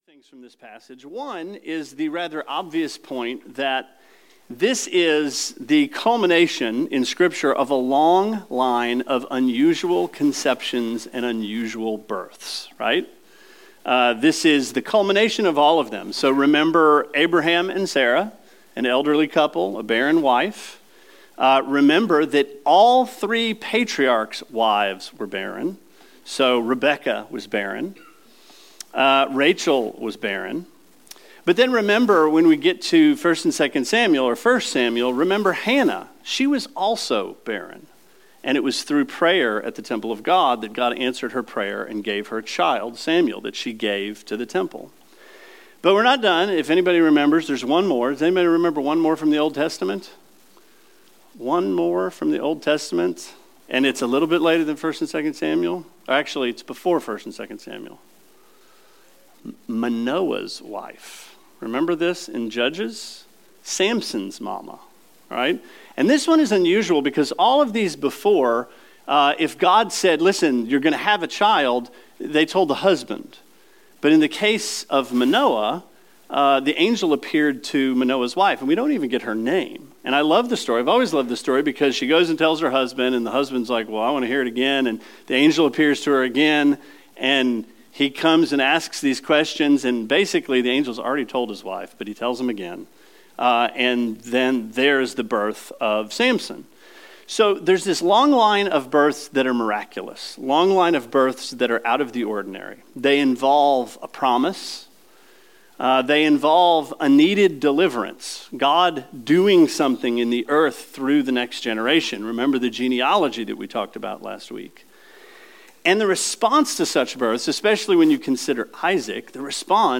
Sermon 12/06: Matthew 1:18-25